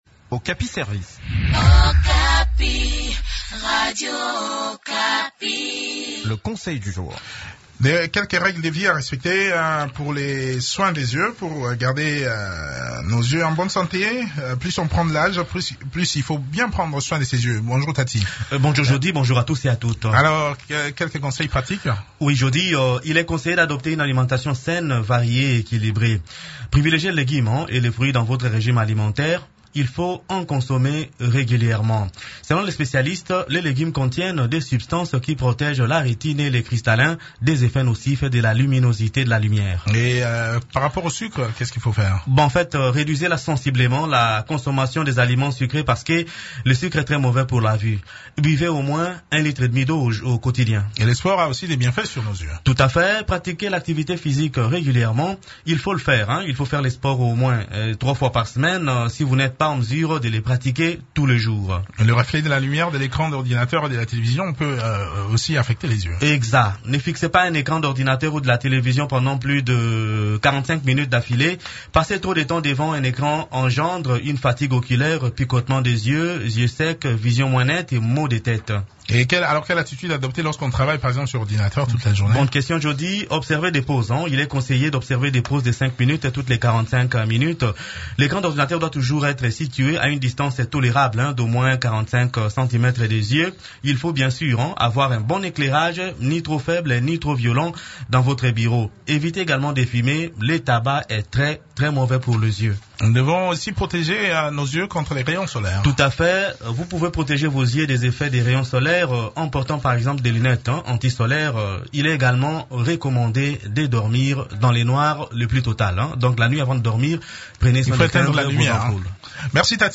Découvrez quelques habitudes à adopter pour garder ses yeux en bonne santé dans cette chronique